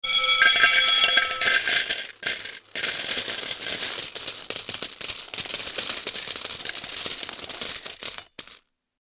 Jackpot Sound Effect
Retro slot machine win sound with a classic bell and coins falling. Perfect for simulating a payout from an old-school one-armed bandit in a casino.
Jackpot-sound-effect.mp3